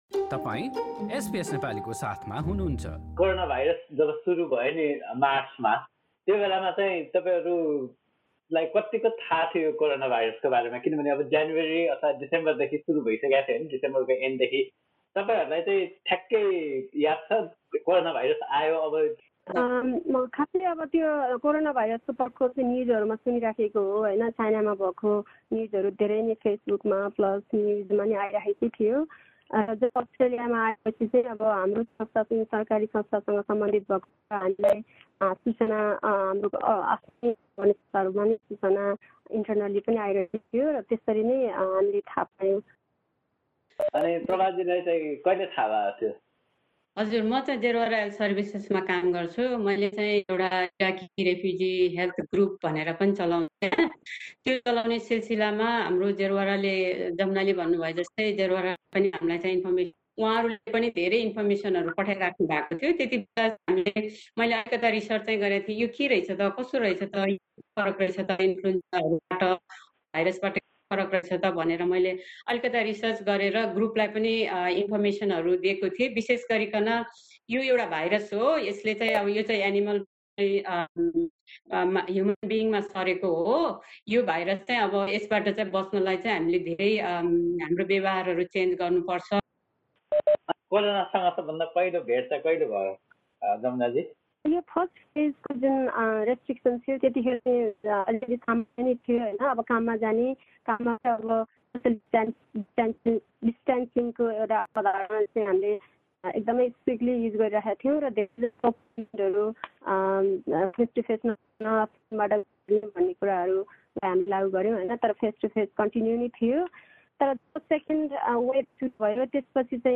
मेलबर्नको बहुसाँस्कृतिक समुदायमा कोरोनाभाइरस रोकथामका चुनौतीहरूबारे दुई नेपाली नर्सको भनाई